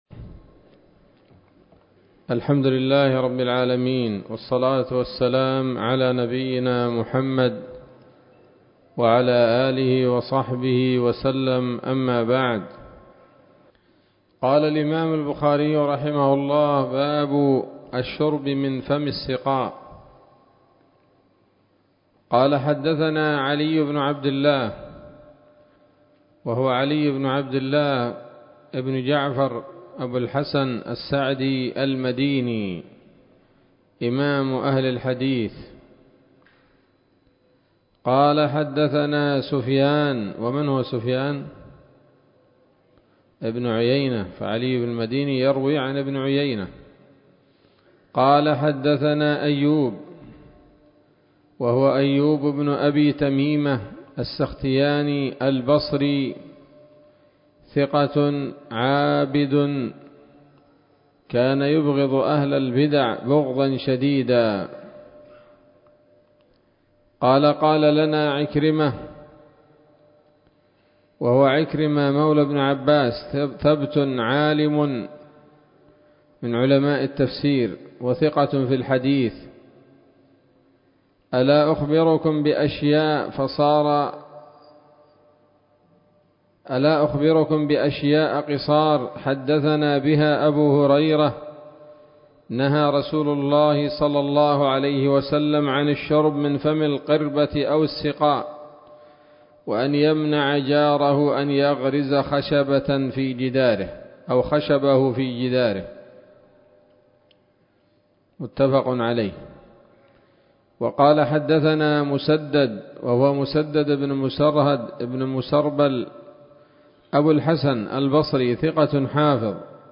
الدرس الثامن عشر من كتاب الأشربة من صحيح الإمام البخاري